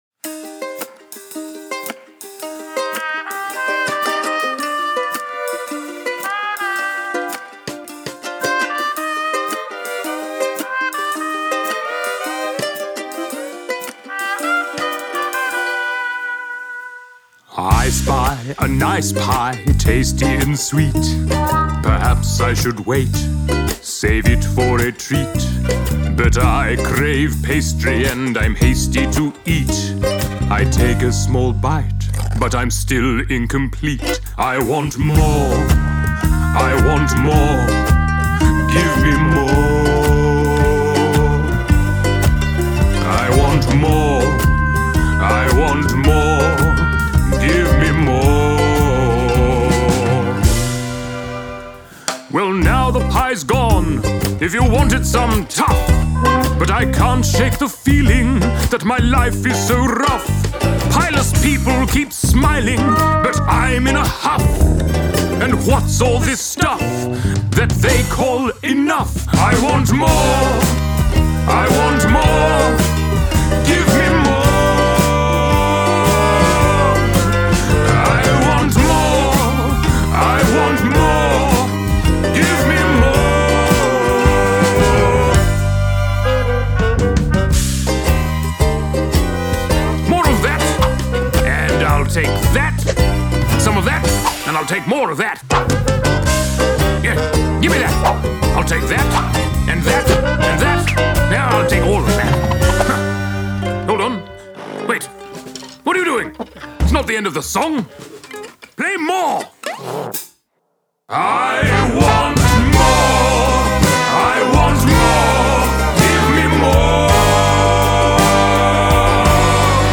An epic Broadway-style show-tune about the perils of greed.